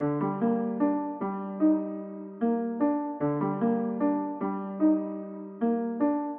描述：我做的钢琴，听起来像一个年轻的暴徒类型的节拍。
Tag: 150 bpm Trap Loops Piano Loops 1.08 MB wav Key : Unknown